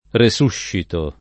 risuscito [ ri S2 ššito ]